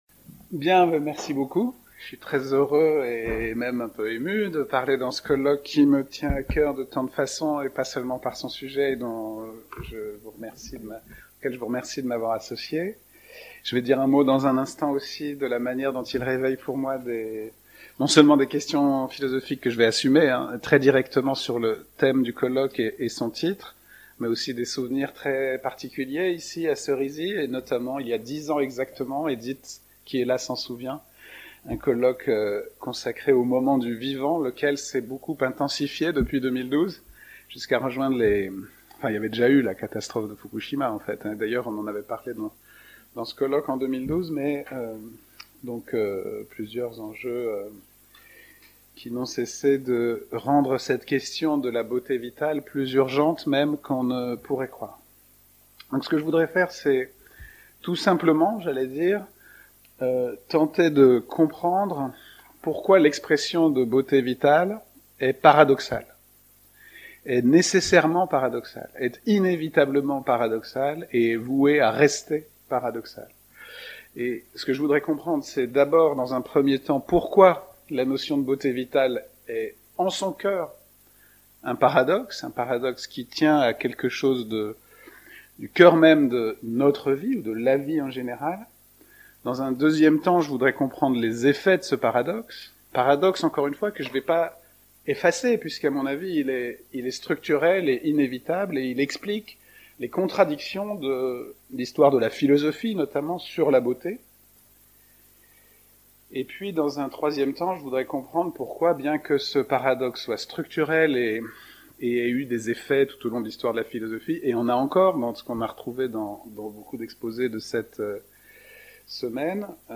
La beauté est vitale parce que la vie est relationnelle, telle est la thèse défendue dans cette conférence.